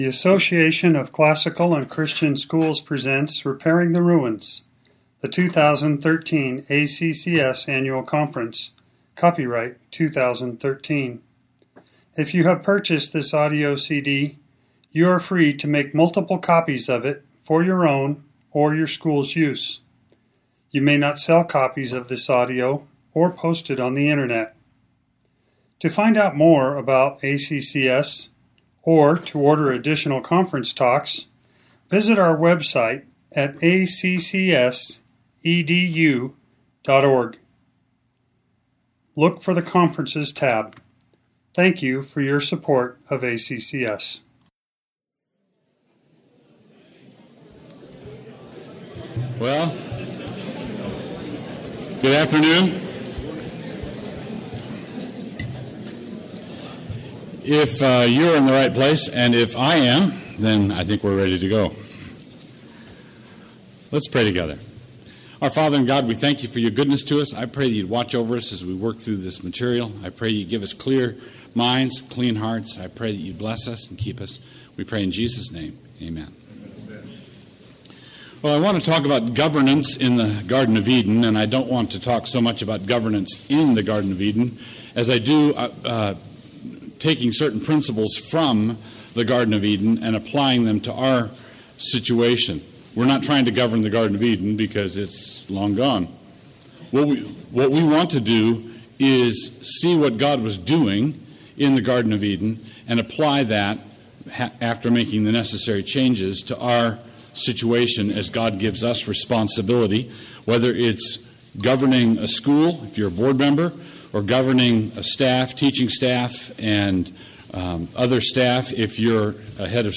2013 Workshop Talk | 1:00:22 | All Grade Levels, Virtue, Character, Discipline